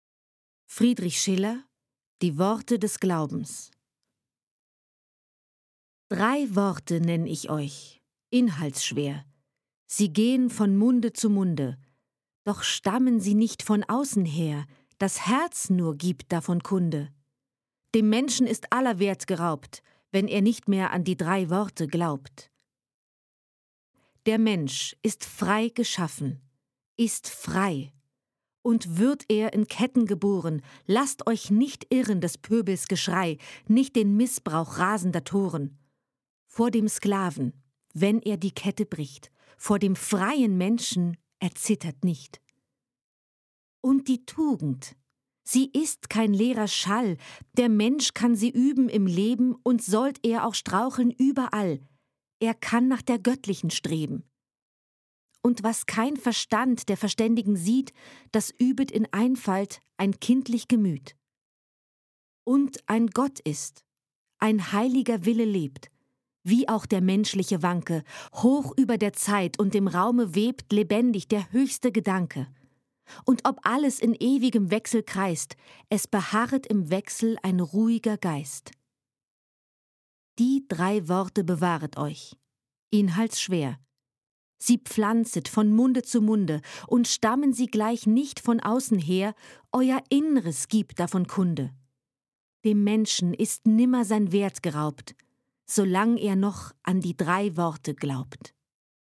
Lyrik, Schullektüre
Die Worte des Glaubens – Gedicht von Friedrich Schiller (1759-1805)